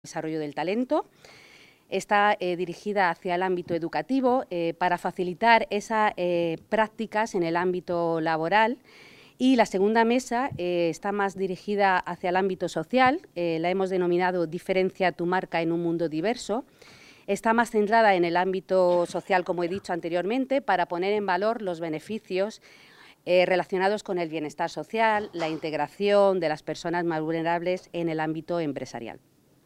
Declaraciones de la concejala Beatriz Cano